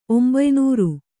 ♪ ombaynūru